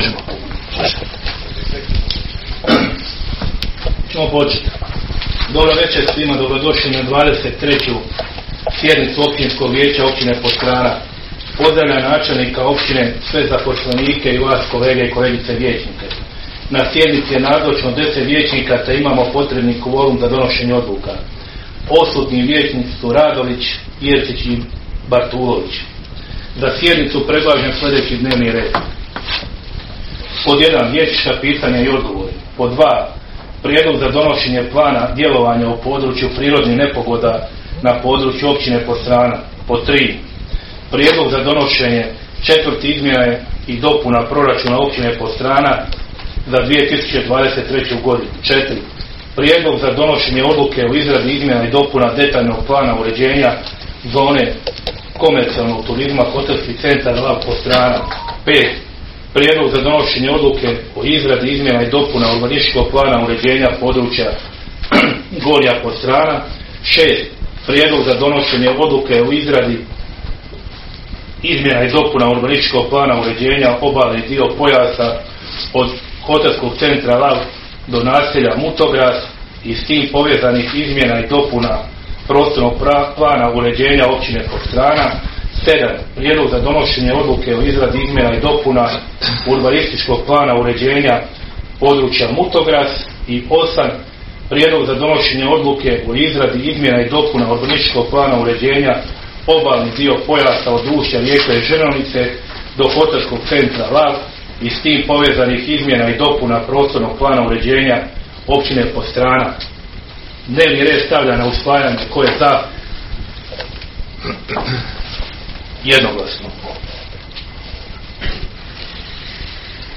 Sjednica će se održati dana 21. prosinca (četvrtak) 2023. godine u 19,00 sati u vijećnici Općine Podstrana.